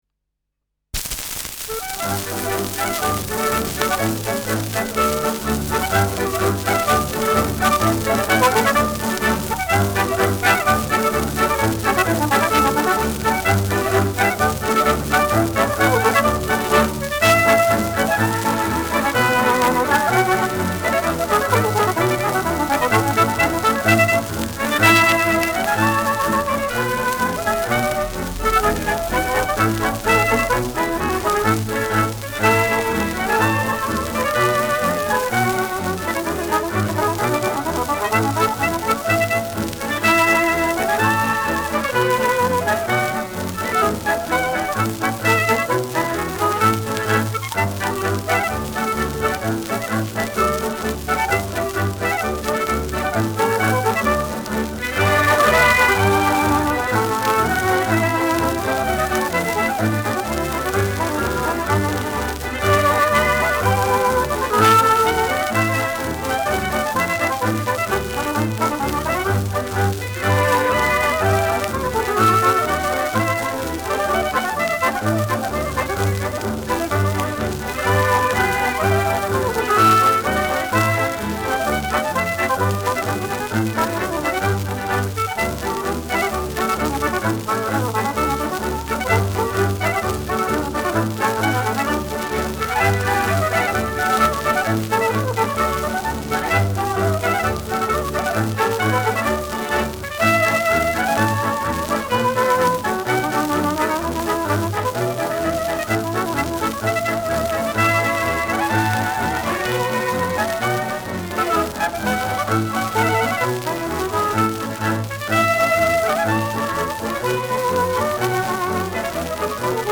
Schellackplatte
präsentes Rauschen
Ländlerkapelle* FVS-00018